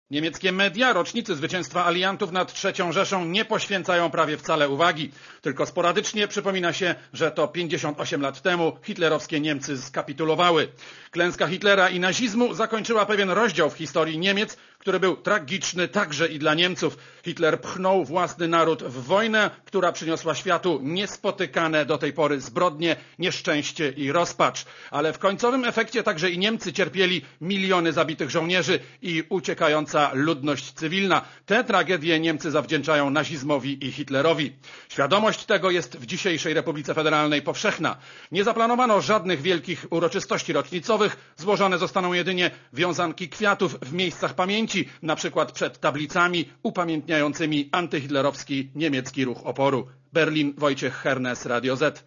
Korespondencja z Berlina (200Kb)